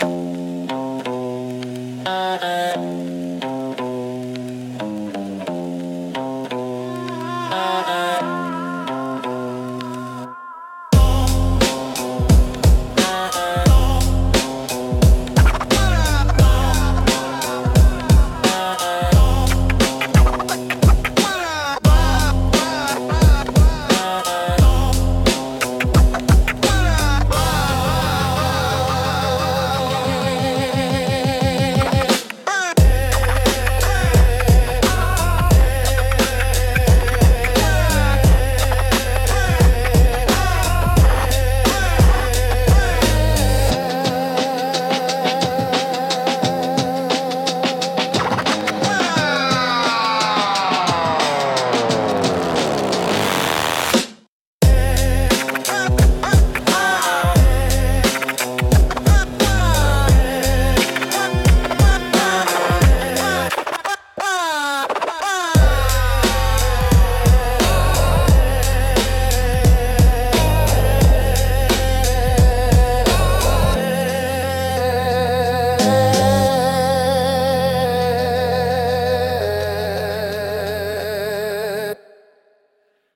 Instrumental - Stutter-Step Gospel